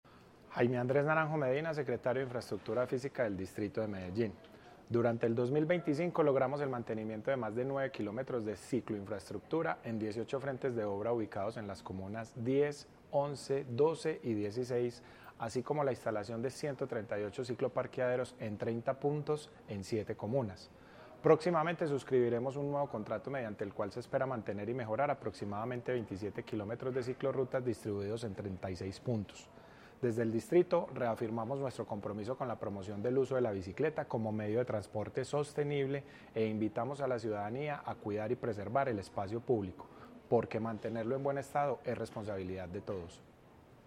Declaraciones-secretario-de-Infraestructura-Fisica-Jaime-Andres-Naranjo-Medina-1.mp3